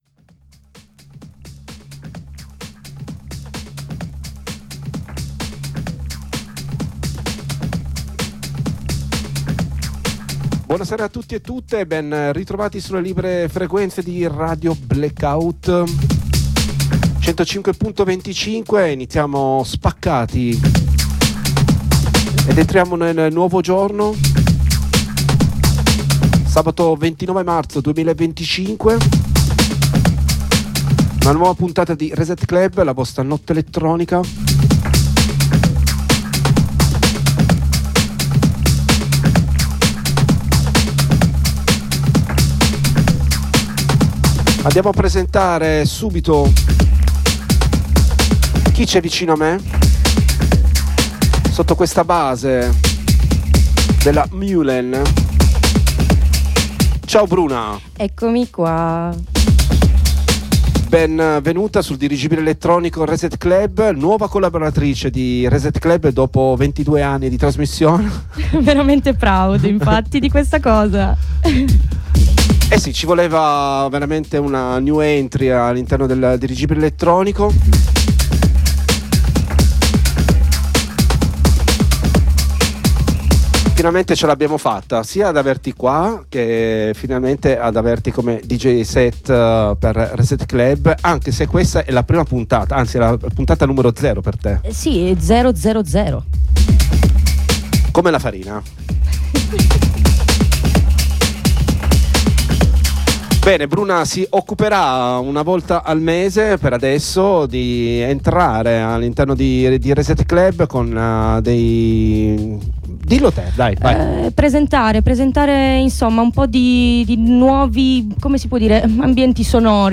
Chiacchierata 1 Parte https